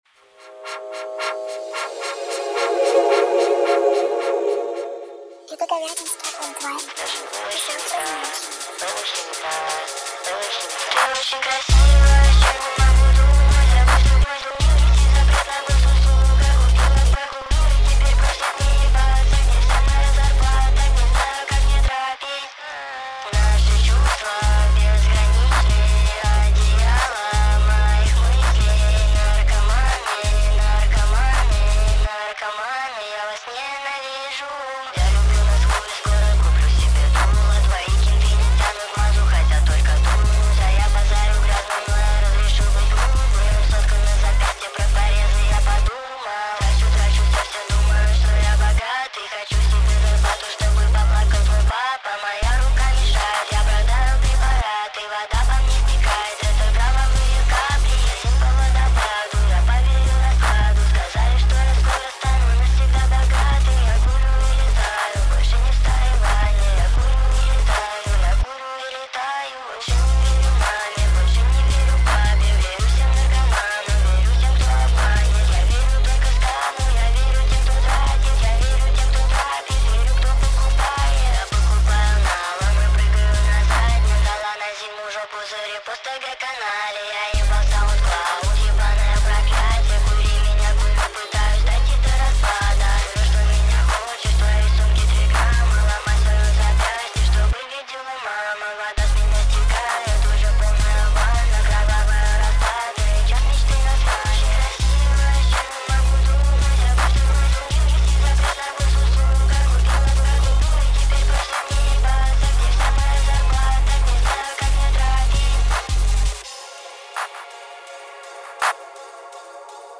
Рэп и Хип-Хоп